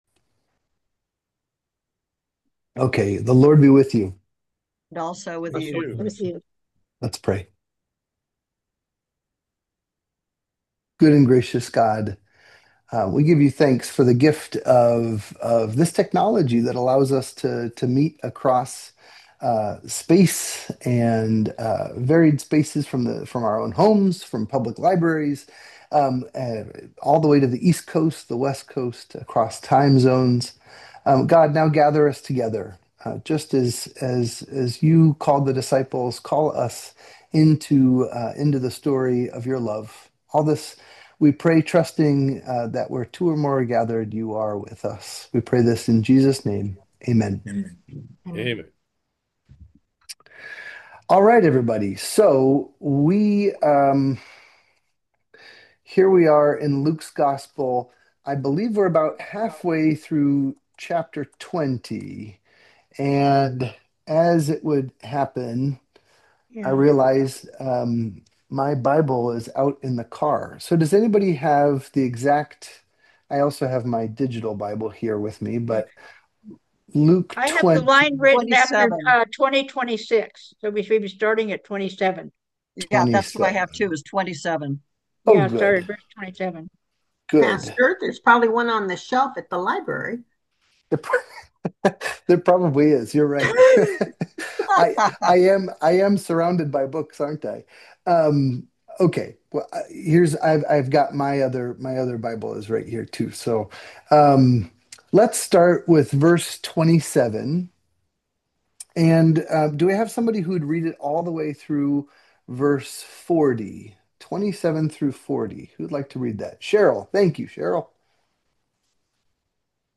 Goats Bible Study
The Goats continue to discuss Luke chapters 20 and into 21, wondering about the relationship between faith, wealth, generosity, and more.